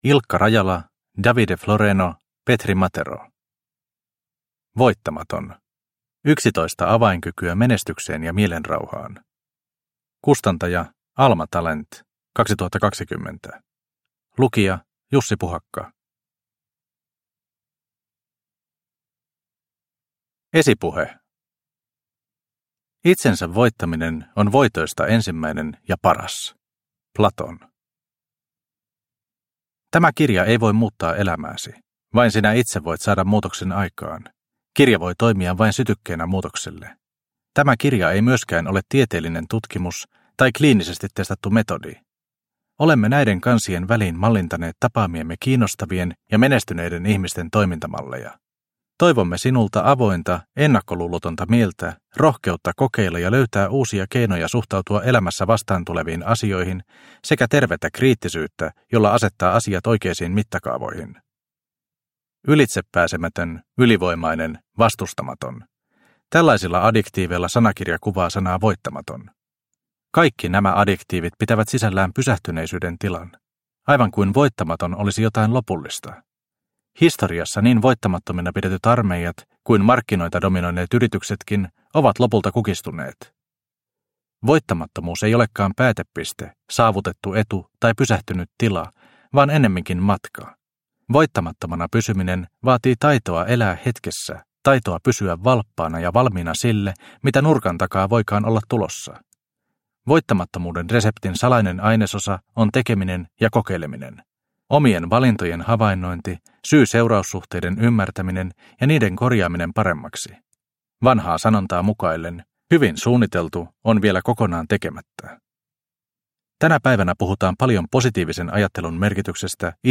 Voittamaton – Ljudbok – Laddas ner